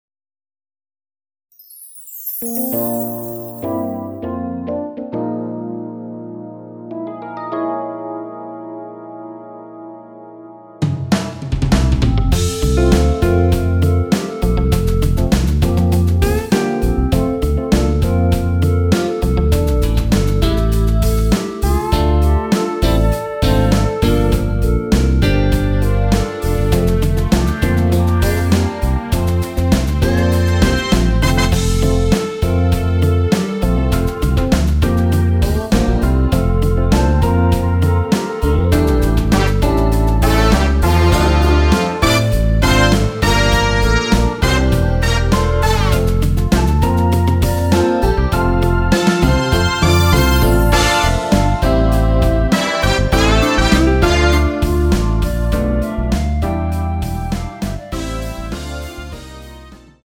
원키 멜로디 포함된 MR입니다.(미리듣기 확인)
앞부분30초, 뒷부분30초씩 편집해서 올려 드리고 있습니다.
중간에 음이 끈어지고 다시 나오는 이유는
(멜로디 MR)은 가이드 멜로디가 포함된 MR 입니다.